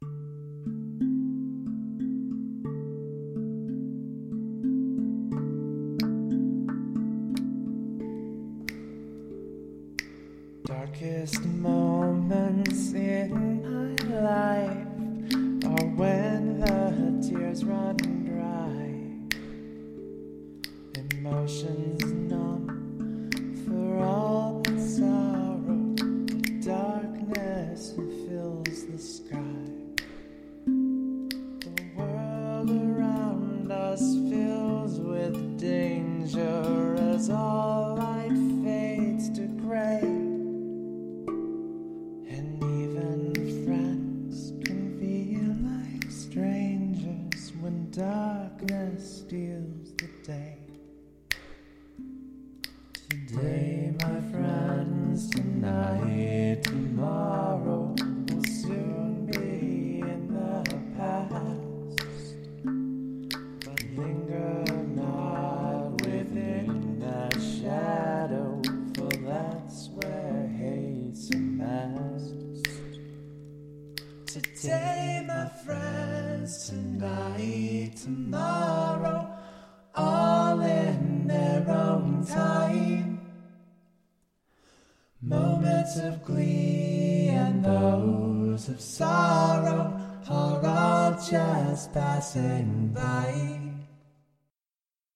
This is not a happy song, but I did my best to make it a beautiful one.